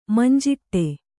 ♪ manjiṭṭe